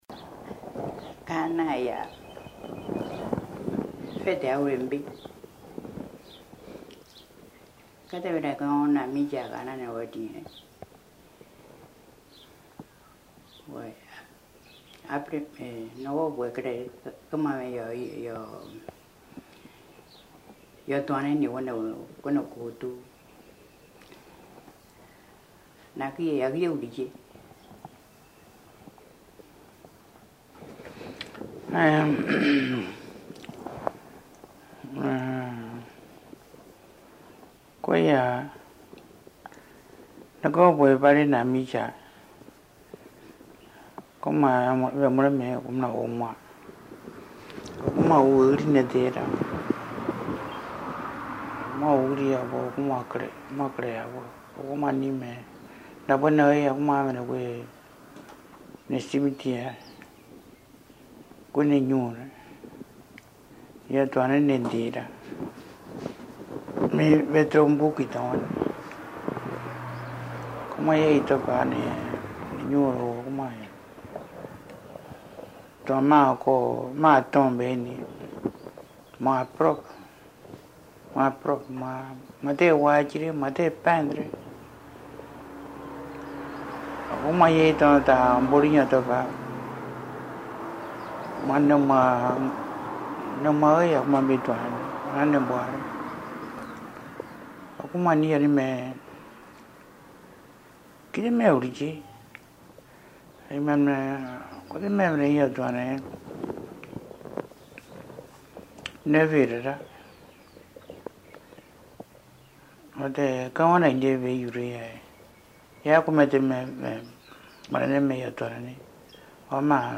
Accueil > Dialogue > Dialogue > Drubéa